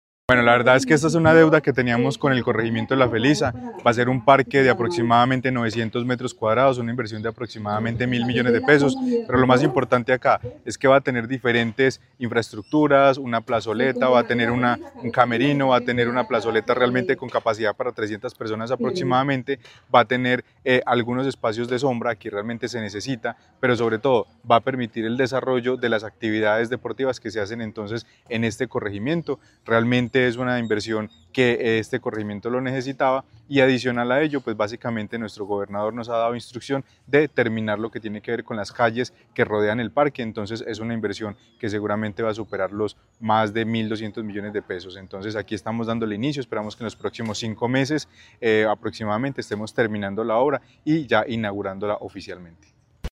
Secretario de Planeación de Caldas, Carlos Anderson García.